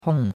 hong4.mp3